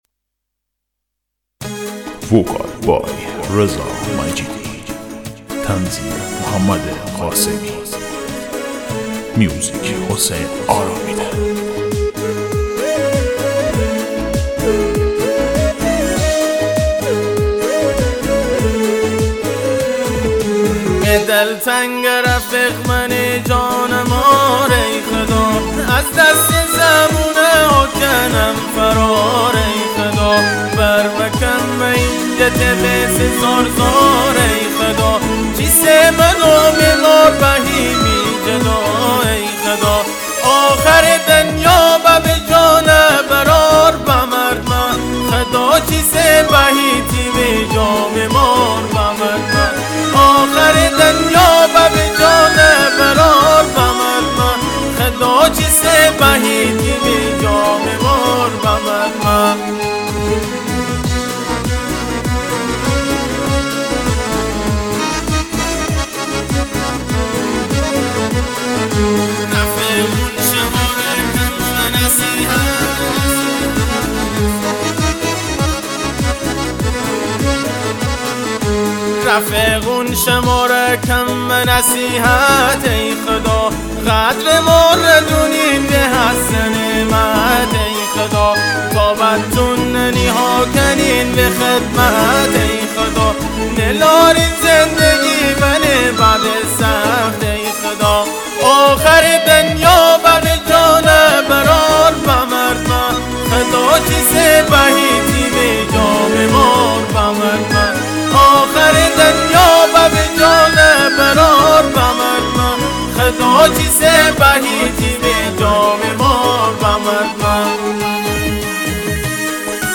دانلود آهنگ مازندرانی جدید
آهنگ غمگین